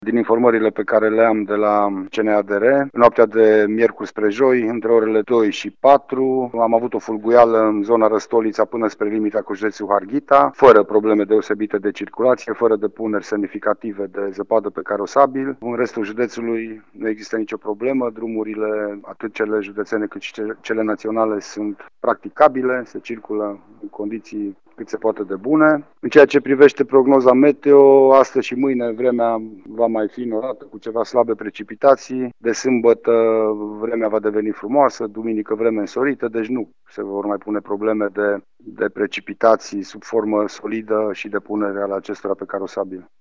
Prefectul județului Mureș, Lucian Goga.